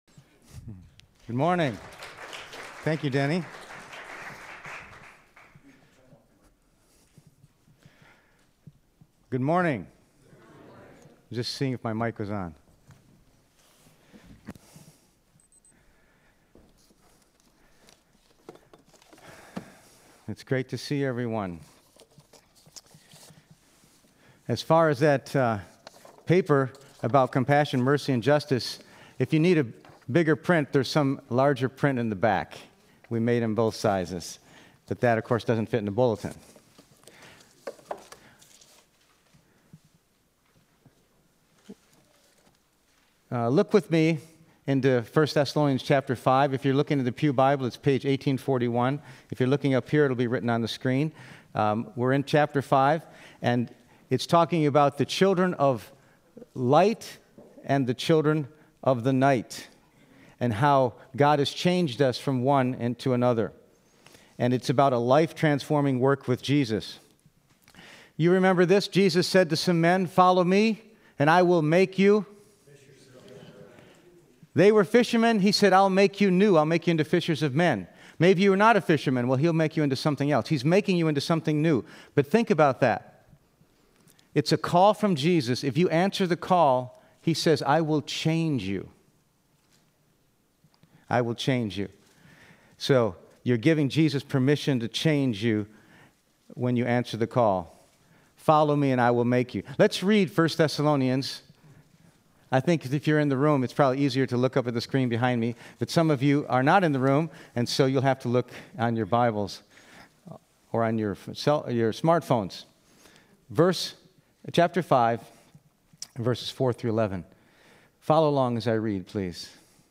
Passage: 1 Thessalonians 5:4-11 Service Type: Sunday Morning %todo_render% « Rescue